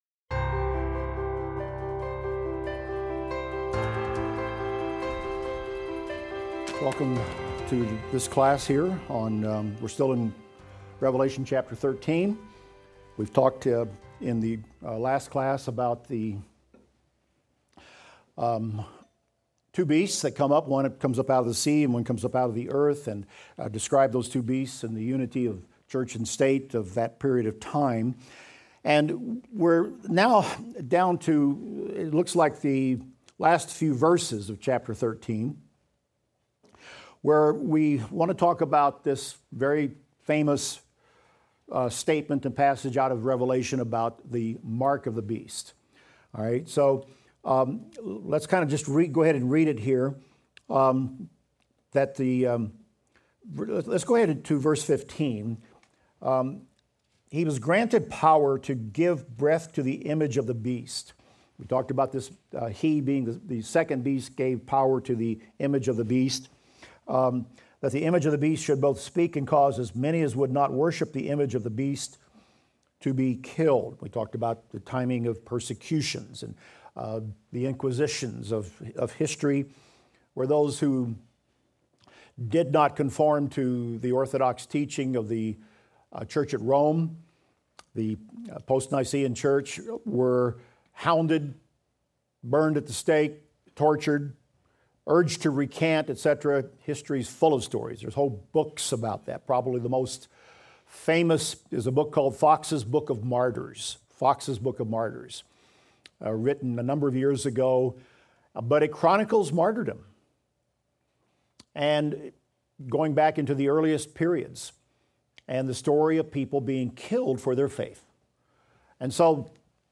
Revelation - Lecture 44 - audio.mp3